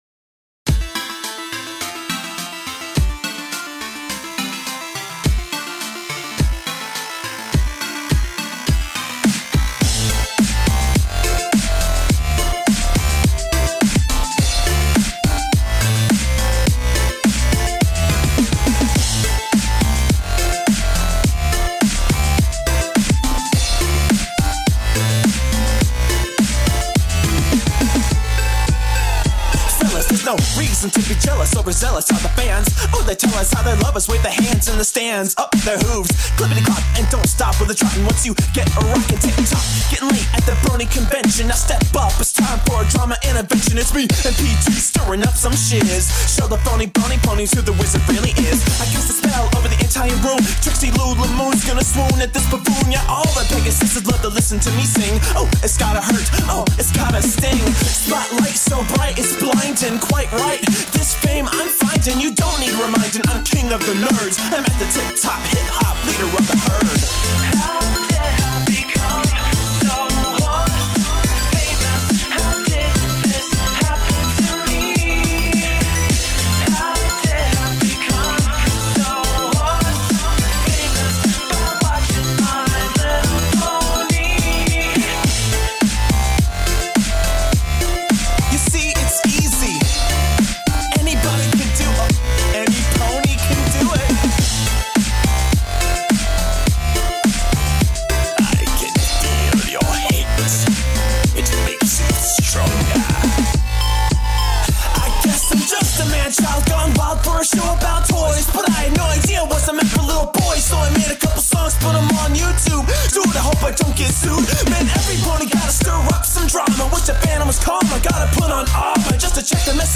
The song is in the key of Am, and the BPM is 105.